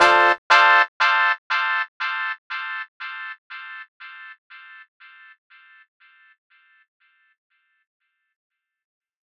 Reg Horn Delay.wav